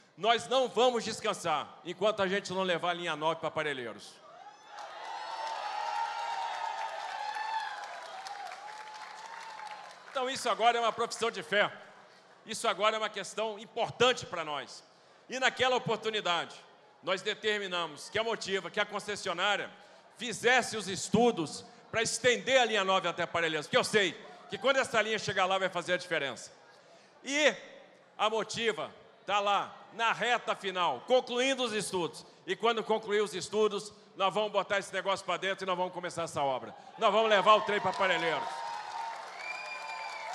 A promessa foi feita pelo governador de São Paulo, Tarcísio de Freitas, nesta segunda-feira, 13 de abril de 2026, em cerimônia de apresentação do Terminal de Ônibus anexo à estação Varginha, terminal da linha que, no outro extremo, para em Osasco, na Grande São Paulo.